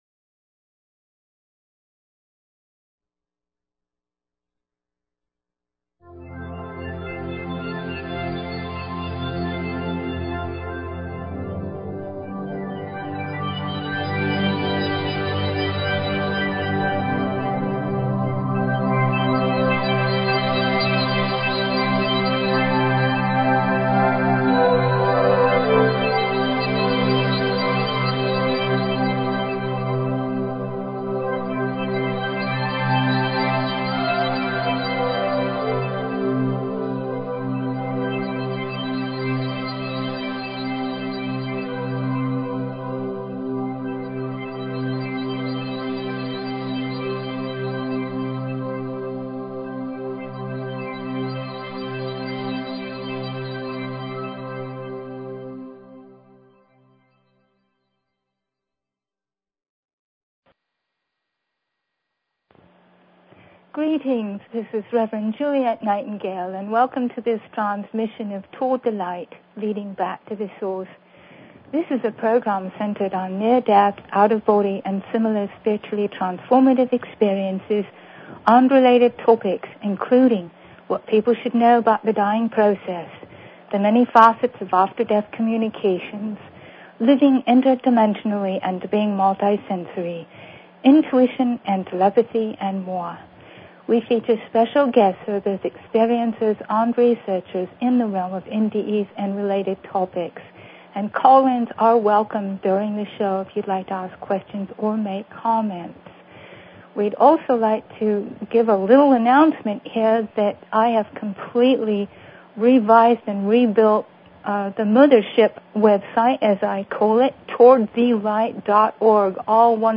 Talk Show Episode, Audio Podcast, Toward_The_Light and Courtesy of BBS Radio on , show guests , about , categorized as
From England, her accent immediately draws people in.